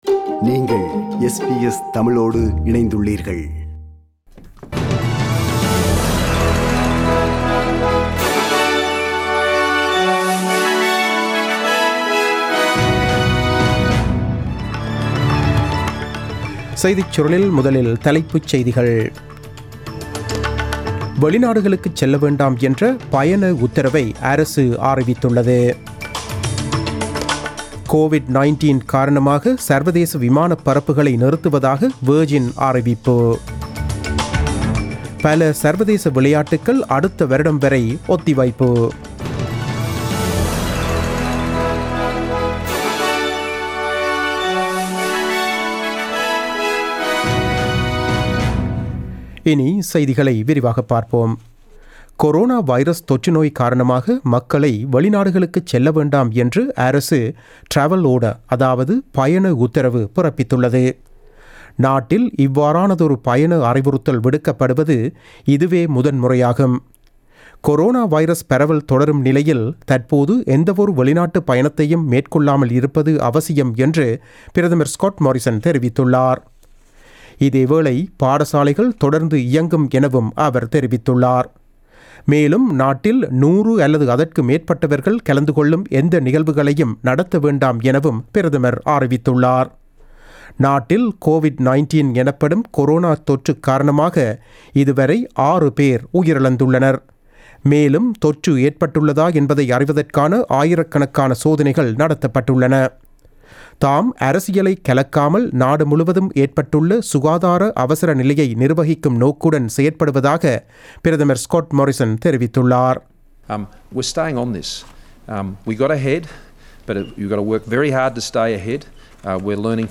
நமது SBS தமிழ் ஒலிபரப்பில் இன்று புதன்கிழமை (18 March 2020) இரவு 8 மணிக்கு ஒலித்த ஆஸ்திரேலியா குறித்த செய்திகள்.